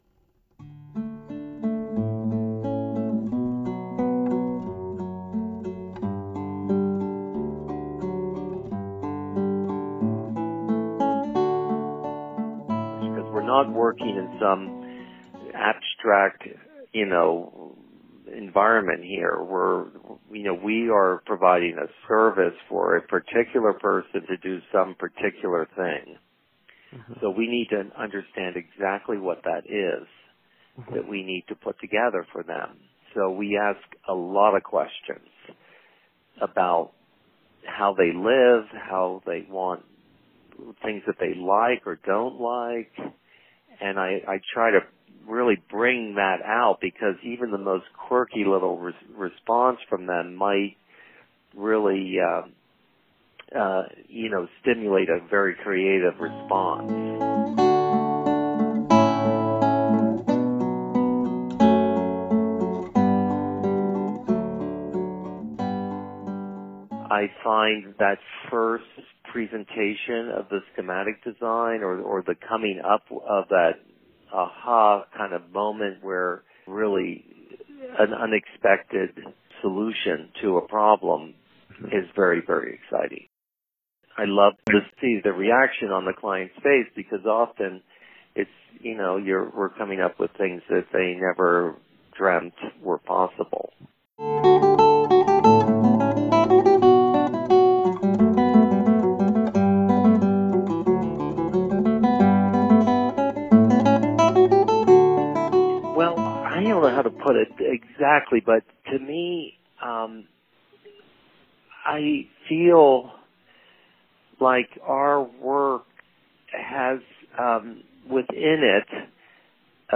Quiet-spoken, charming and articulate, it is immediately apparent that he is not only steeped in history but is also very knowledgeable of current industry patterns and innovations.
First, a few audio highlights from our conversation